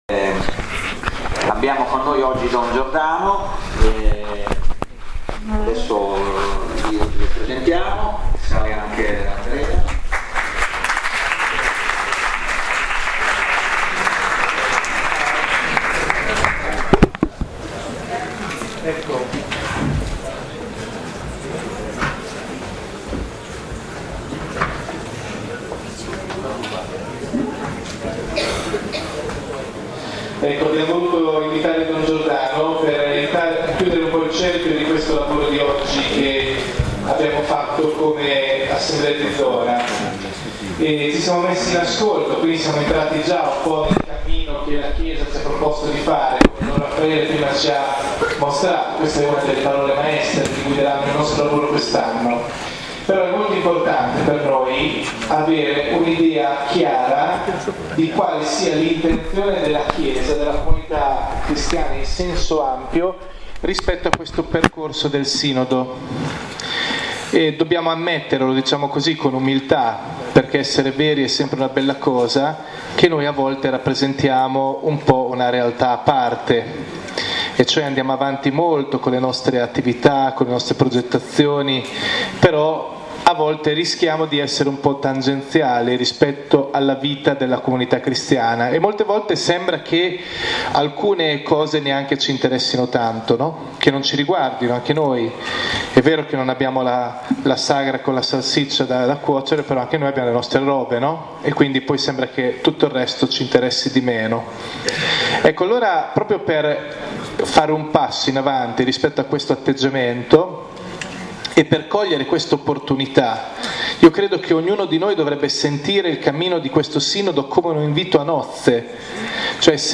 ASSEMBLEA DI ZONA Nov 2017